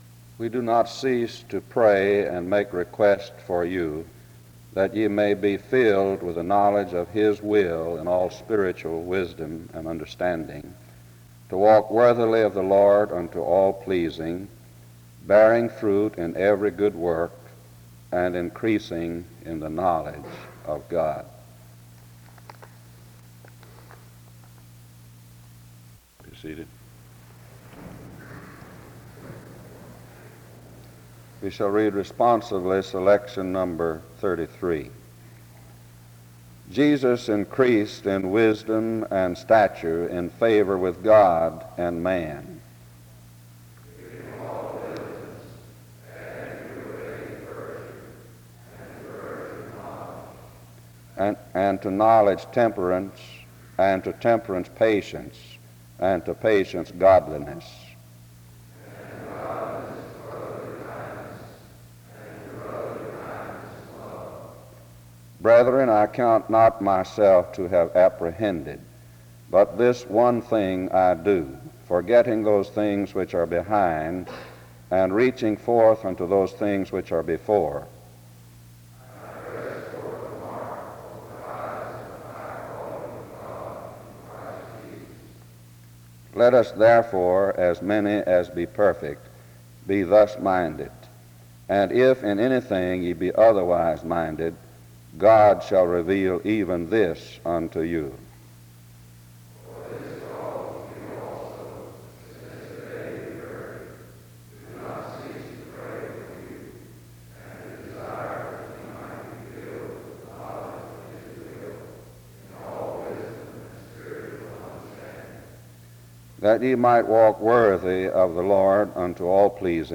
The service begins with prayer from 0:00-0:25. A responsive reading takes place from 0:30-2:15. A prayer takes place from 2:20-5:33. Music plays from 5:34-8:01.